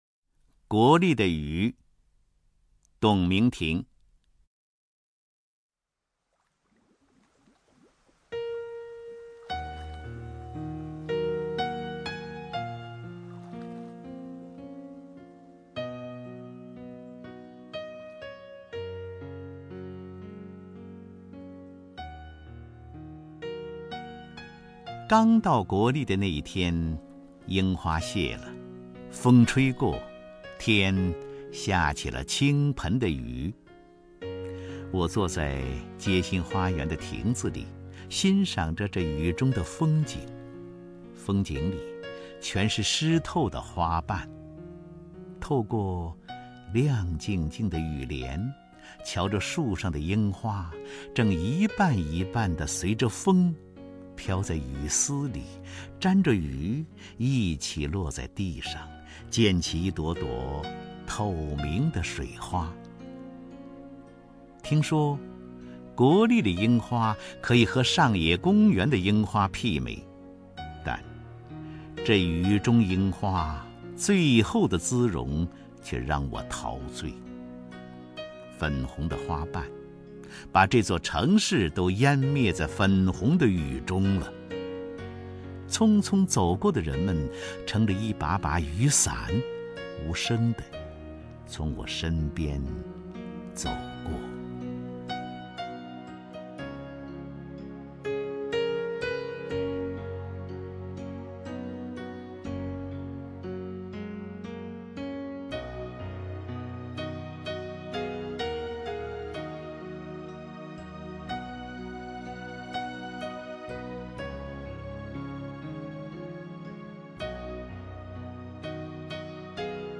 首页 视听 名家朗诵欣赏 张家声
张家声朗诵：《国立的雨》(董鸣亭)